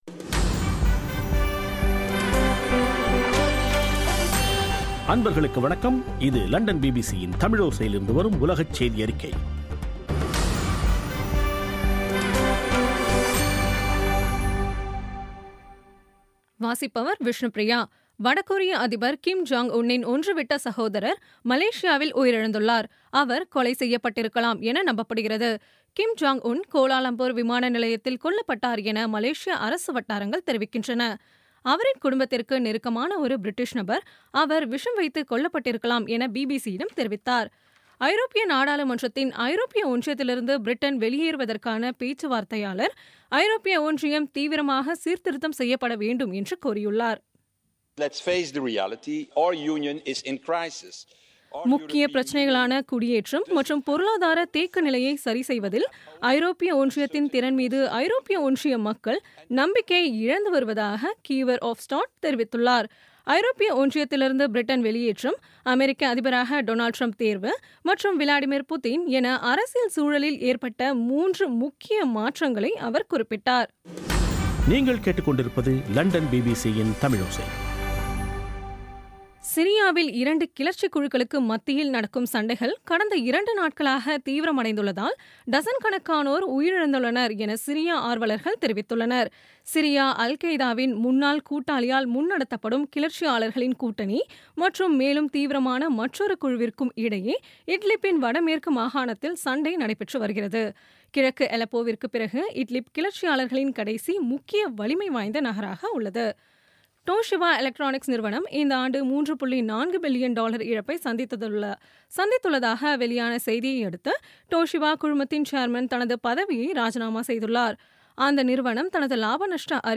பிபிசி தமிழோசை செய்தியறிக்கை (14/02/2017)